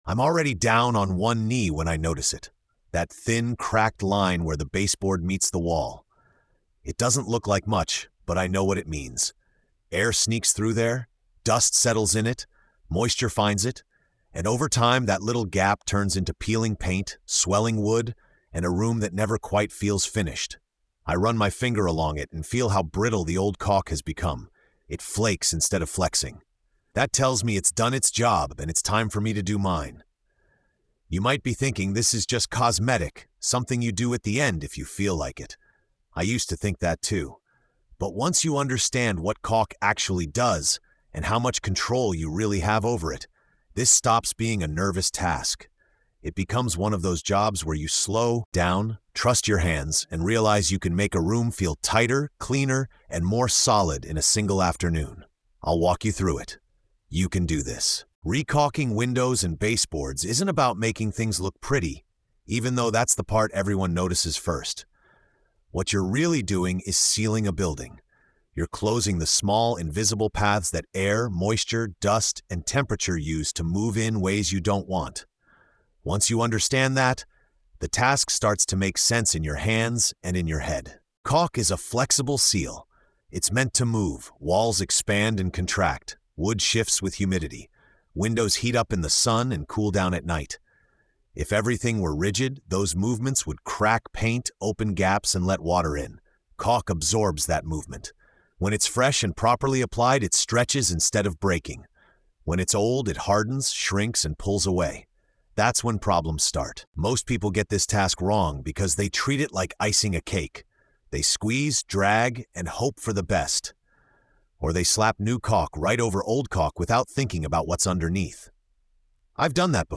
In this episode of TORQUE & TAPE — The Blue-Collar Skills, an experienced tradesman walks the listener through the often-overlooked craft of re-caulking windows and baseboards.
Told entirely in a calm, first-person teaching voice, the episode blends real job-site experience with practical wisdom, showing how small details can dramatically change how a room feels, seals, and lasts over time. The tone is steady and encouraging, guiding DIY homeowners away from rushed mistakes and toward professional-level results.